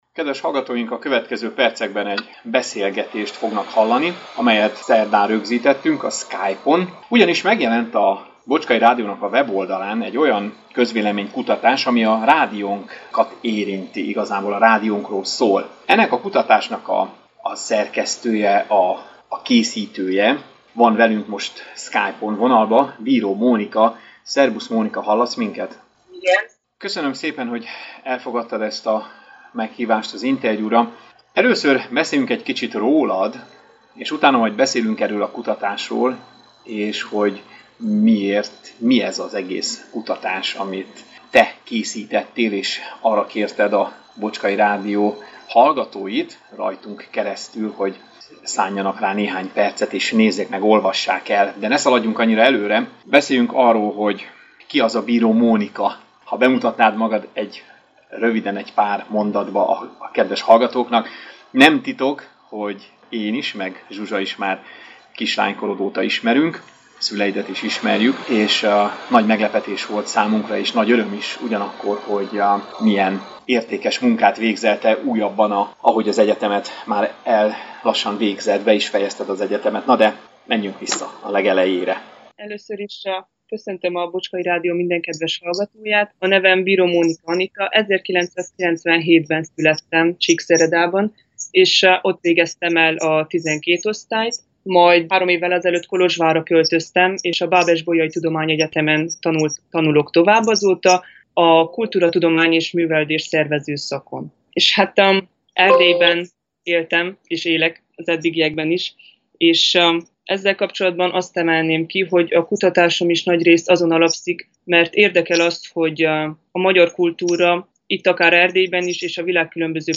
Elnézést a hang minőségéért, vannak benne kisebb zörejek amelyek a felvételnél keletkeztek.